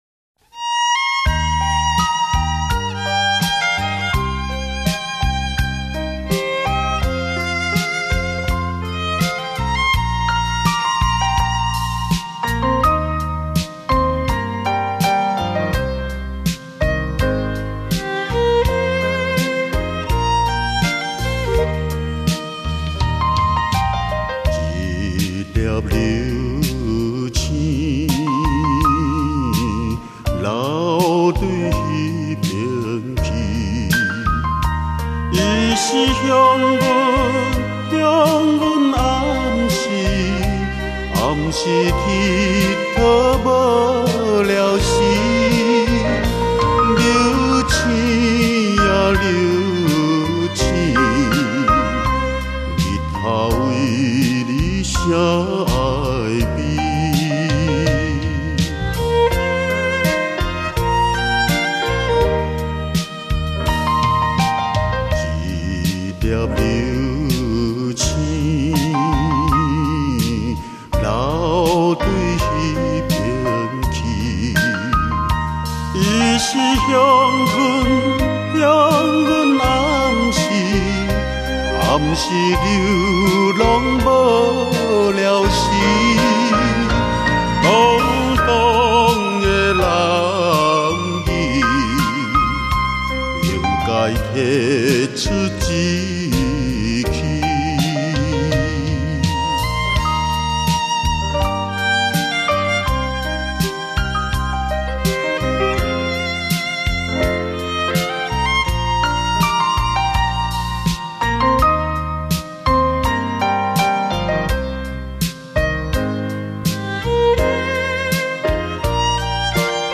性    别： 男
分    类： 华语歌曲